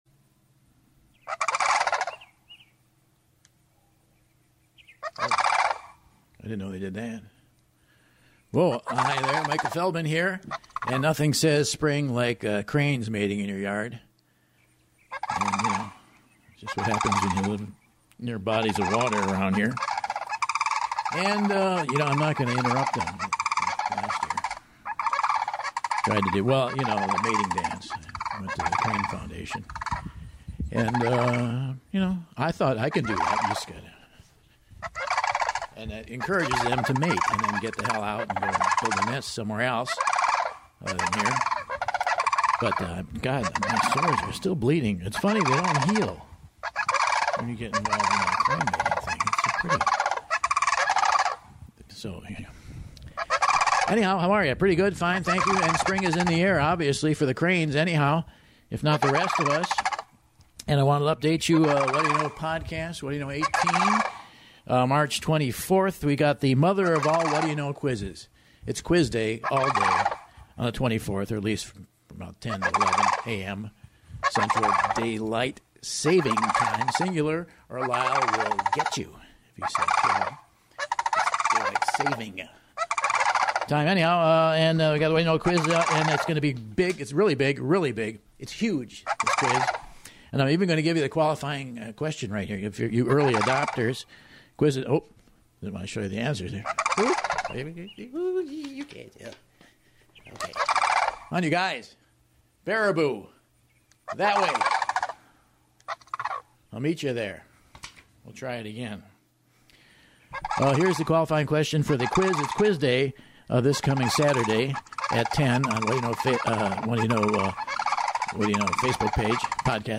Surrounded by mating Cranes, Michael Feldman attempts to update fans on upcoming Whad’ya Know Podcasts for Spring ’18, before entering the Sandhill fray.